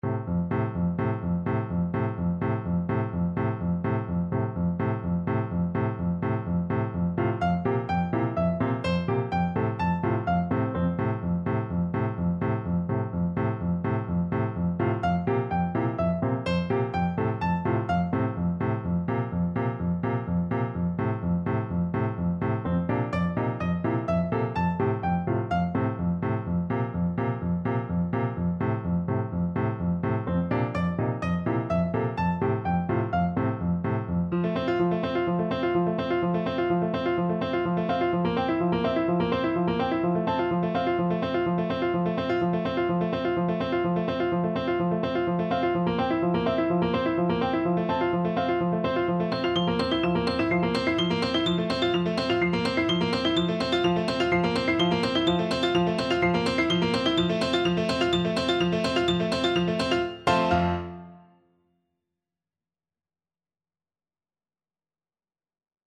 4/4 (View more 4/4 Music)
Allegro moderato (=126) (View more music marked Allegro)
Classical (View more Classical Bassoon Music)